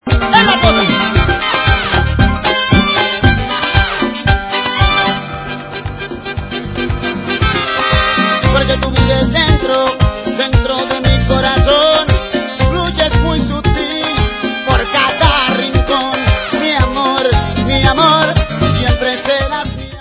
sledovat novinky v oddělení World/Latin